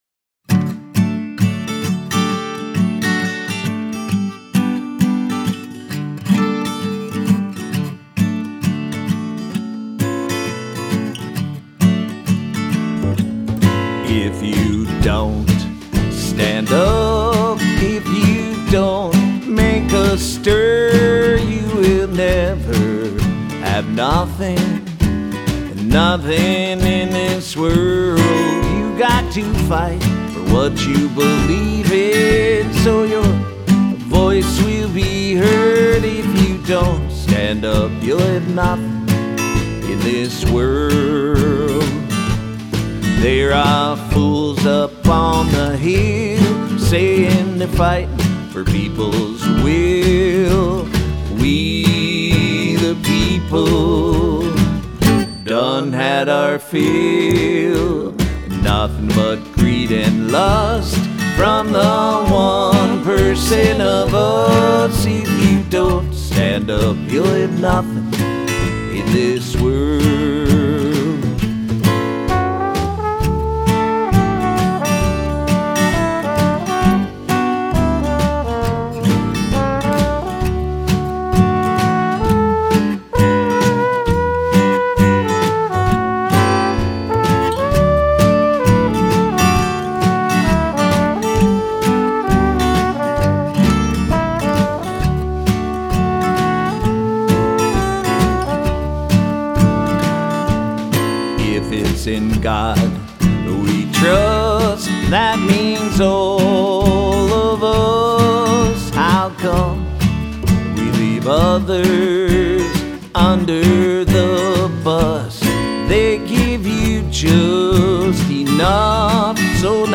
Minnesota Folk Singer and Songwriter